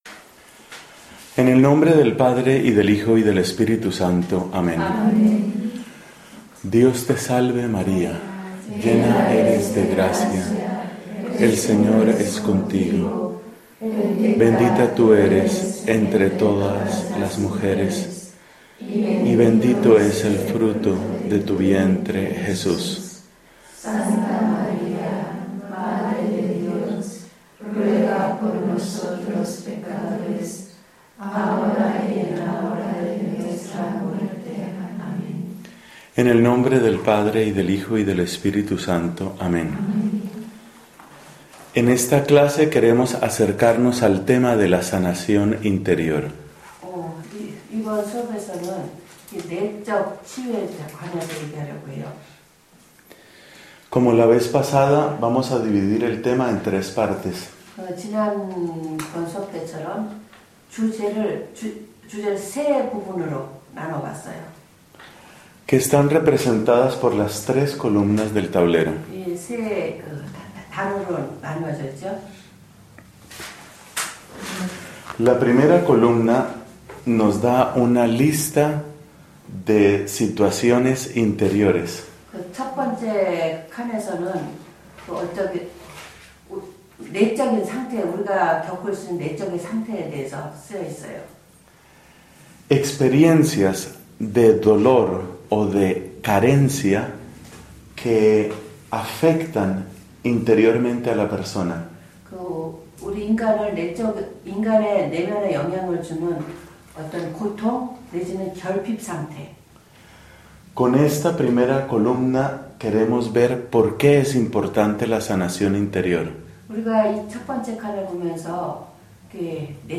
[Serie de catequesis para las Monjas Dominicas del Monasterio de la Madre de Dios, en Baeron, Jenchen, Corea del Sur. Cada predicación fue traducida del español al coreano frase por frase, lo cual da un ritmo diferente a esta serie.]